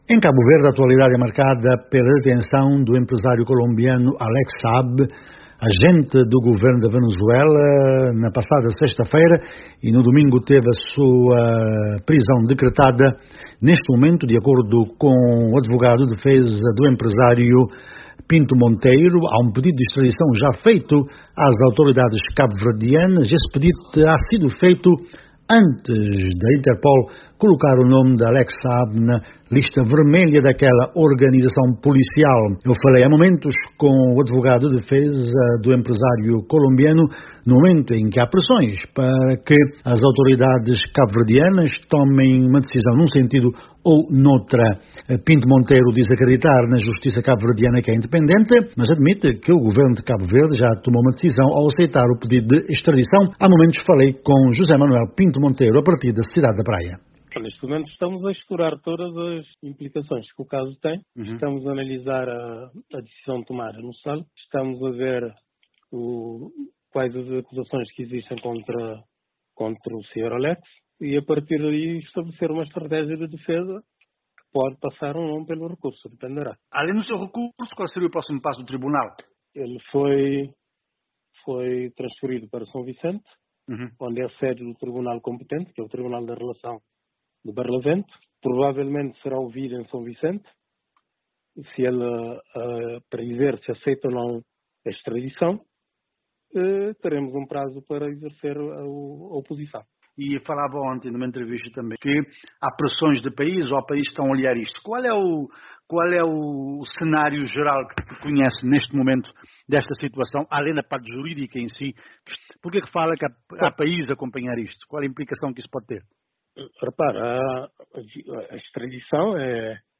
Caso Alex Saab: Advogado de defesa fala em "interferências outras" mas confia na justiça de Cabo Verde